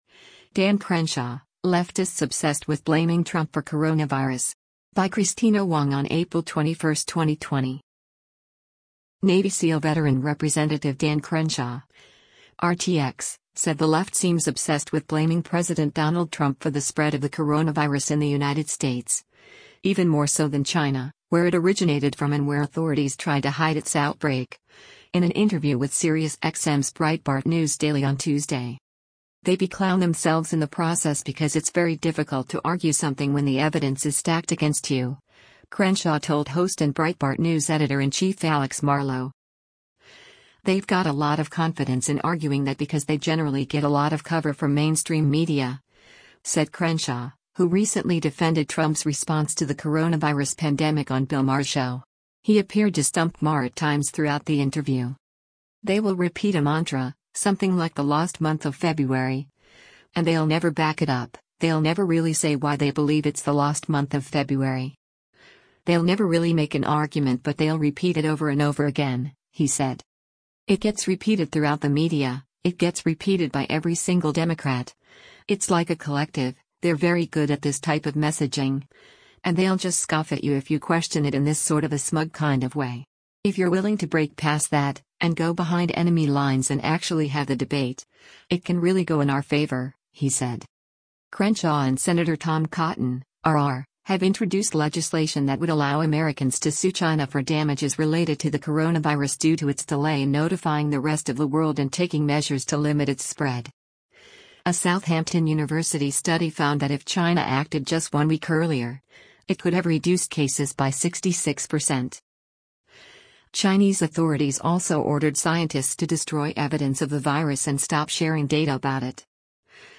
Navy SEAL veteran Rep. Dan Crenshaw (R-TX) said the left seems “obsessed” with blaming President Donald Trump for the spread of the coronavirus in the United States, even more so than China — where it originated from and where authorities tried to hide its outbreak, in an interview with Sirius XM’s Breitbart News Daily on Tuesday.